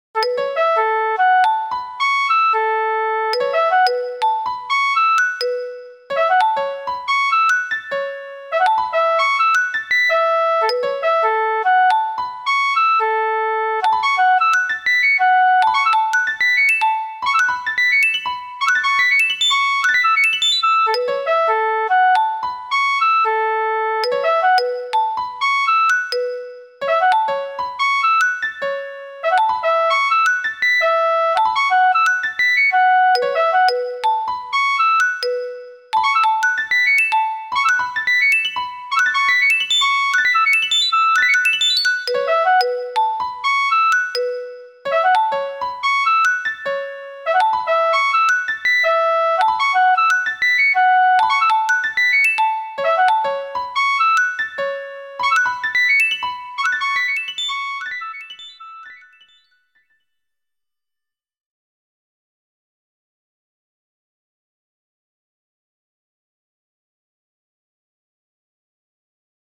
These mp3s were rendered to audio with the Roland Sound Canvas.
So, in these recordings, they fade out at the end of the clip.
flute+.mp3